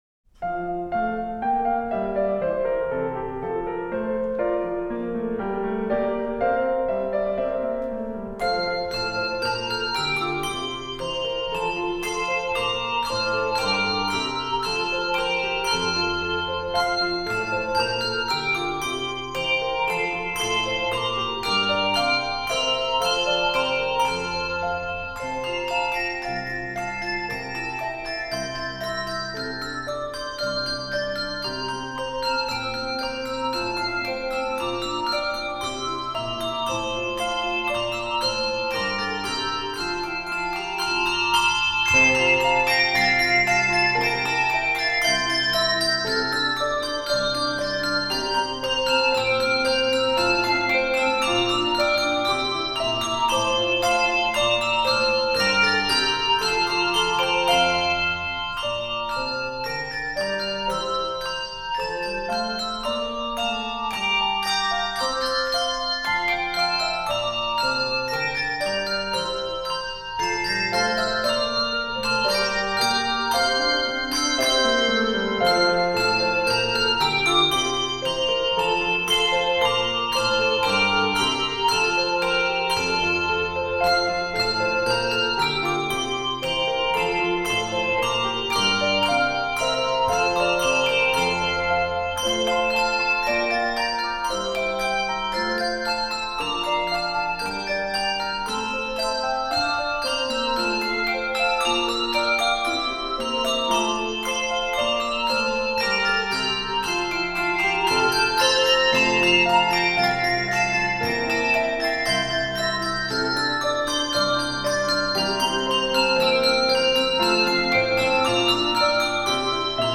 Voicing: 12 Bells